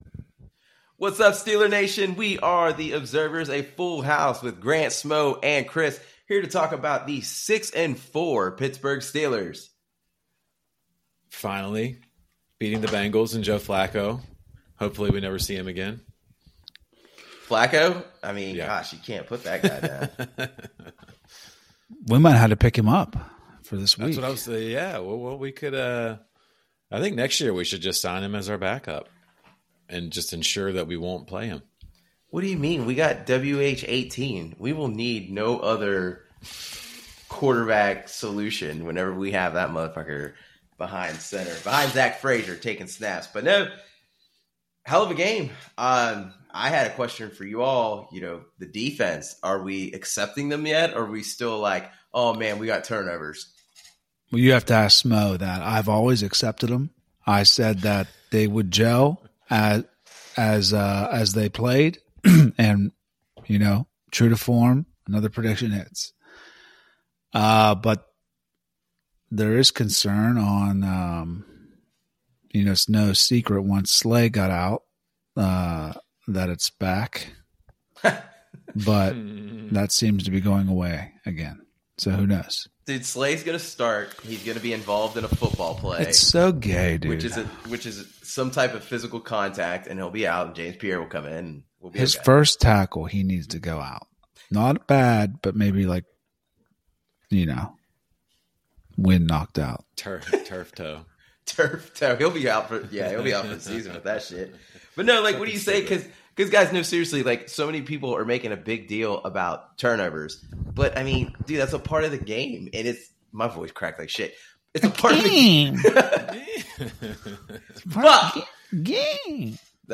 Three friends and diehard Steelers fans bring their iMessage thread to the masses.